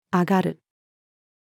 挙がる-female.mp3